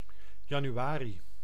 Ääntäminen
IPA: /ˈtɑ.mːiˌkuː/